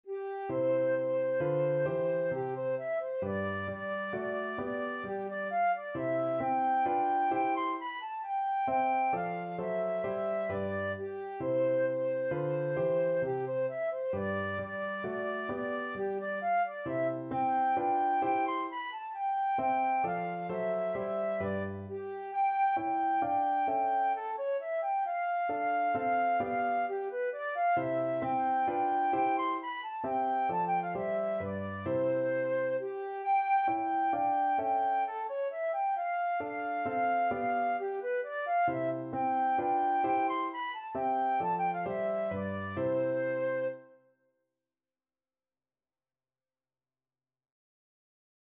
3/4 (View more 3/4 Music)
= 132 Allegro (View more music marked Allegro)
Classical (View more Classical Flute Music)